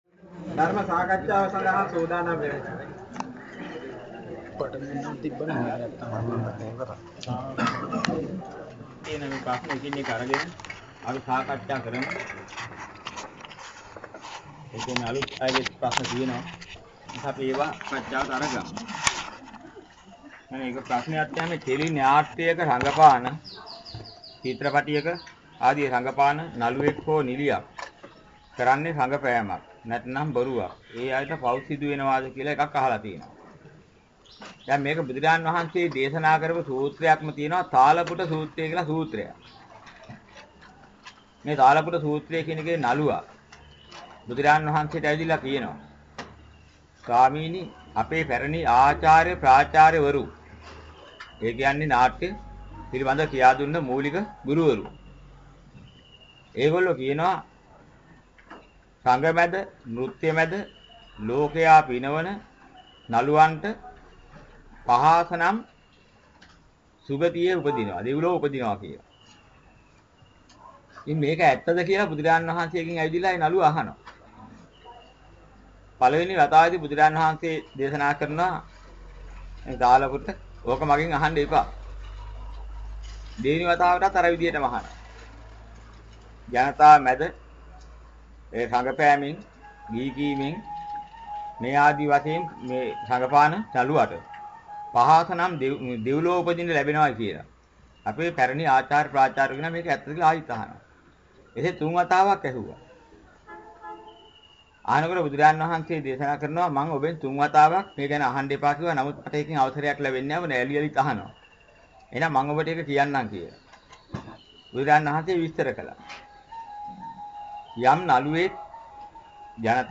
දේශනාවට සවන් දෙන්න (අහන ගමන් කියවන්න)